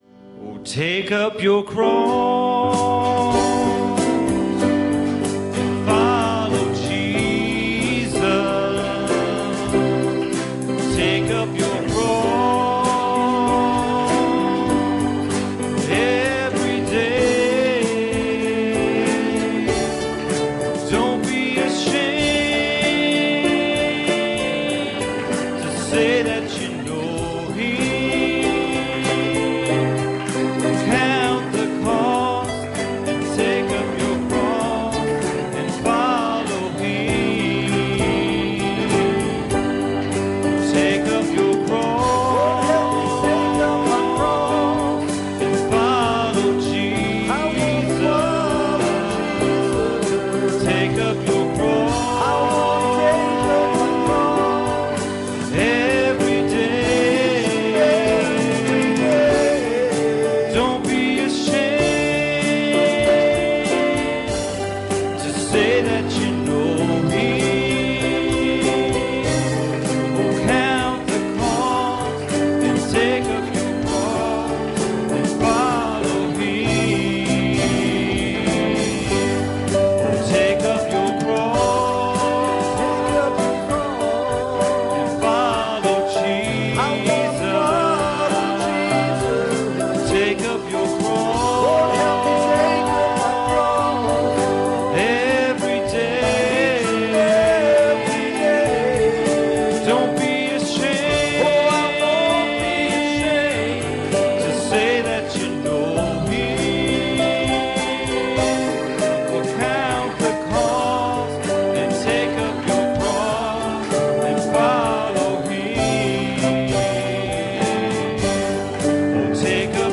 Bible Text: Genesis 3:9 | Preacher
Genesis 3:9 Service Type: Sunday Evening Bible Text